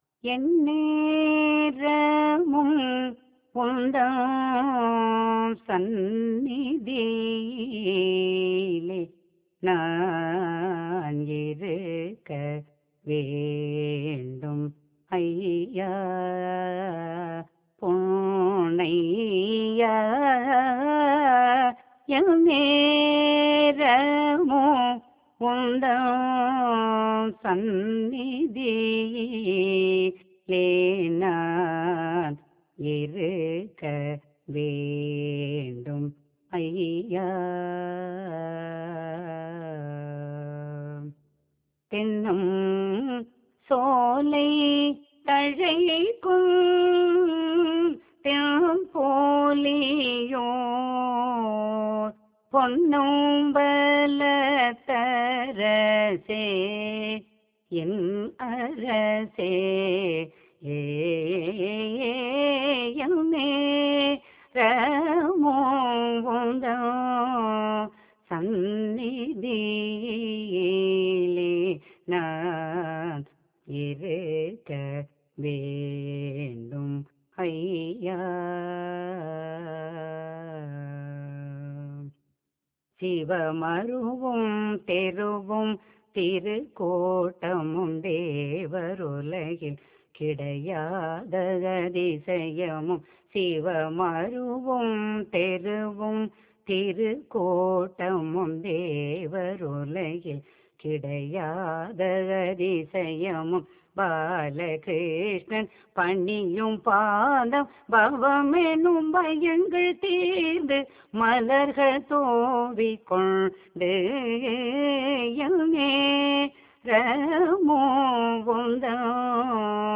இராகம் : தேவகாந்தாரி தாளம்: ஆதி